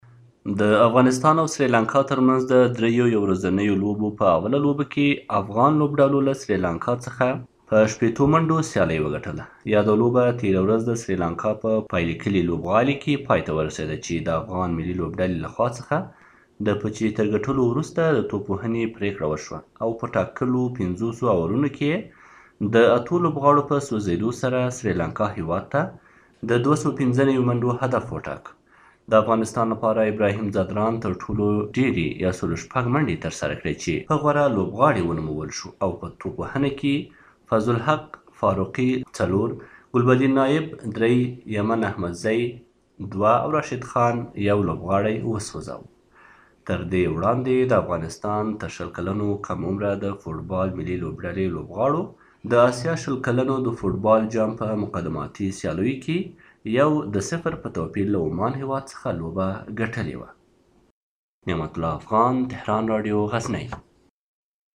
د افغانستان او سریلانکا ترمنځ دوهمه لوبه به د یکشنبې او درېیمه او وروستۍ د سه شنبې په ورځ په پام کې دي په دې اړه له غزني زمونږ دخبریال رالیږلی راپور په ګډه سره آورو .